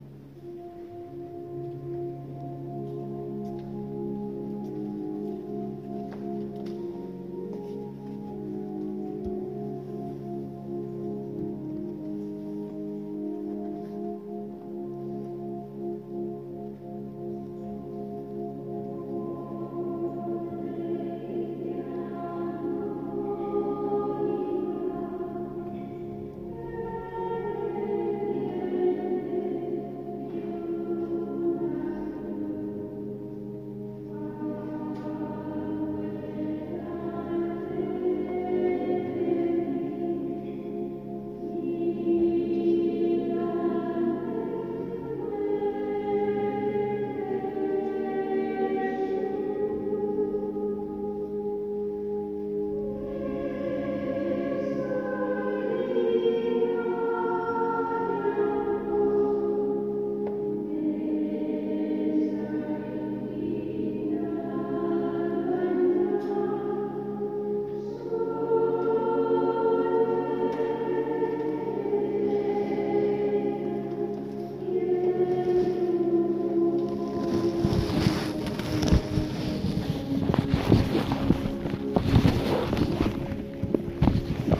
Aujourd’hui étant le premier vendredi du mois, la célébration est un peu spéciale car il y a ensuite adoration et la vingtaine de personnes que nous sommes dans l’assemblée a le bonheur de prier au chant des moniales.
Je vous partage le chant de communion (en m’excusant du bruit à la fin de l’enregistrement).
Ávila-Encarnación-Messe-adoration-chant-des-moniales.m4a